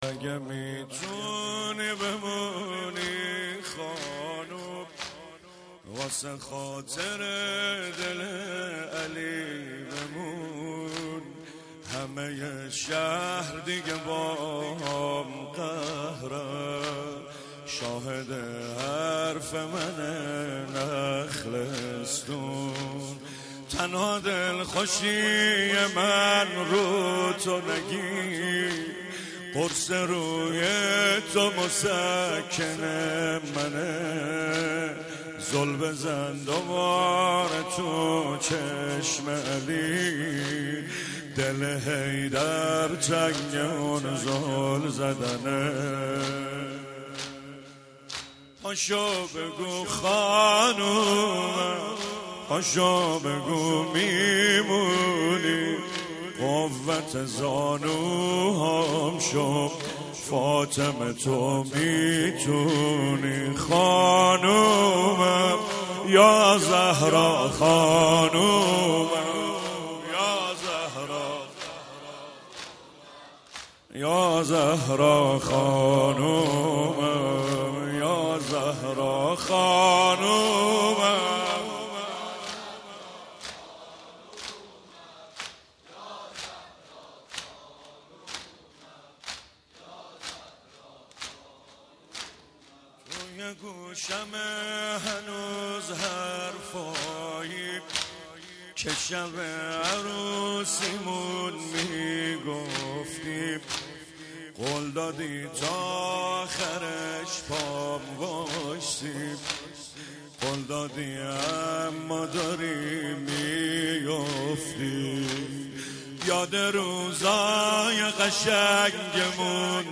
مراسم شب اول فاطمیه ۱۳۹7